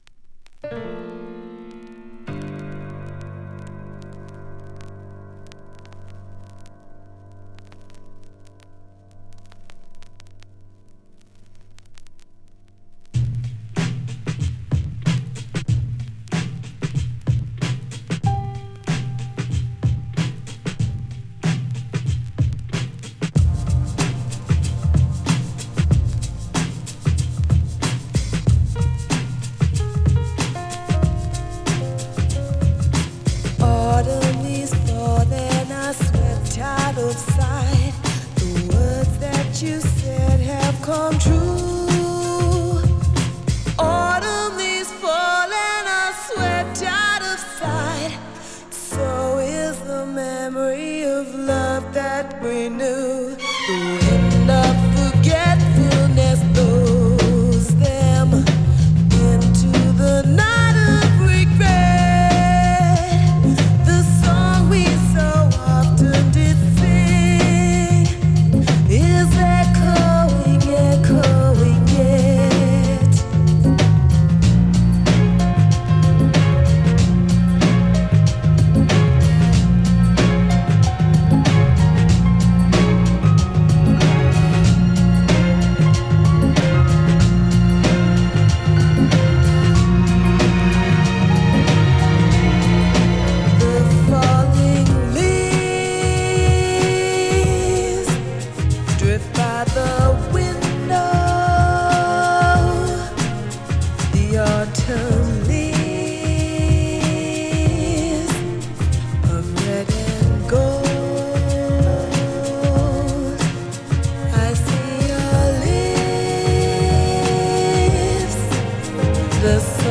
歌い上げる女性ヴォーカルと深遠なストリングスが印象的なトラックです。